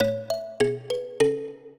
mbira
minuet3-12.wav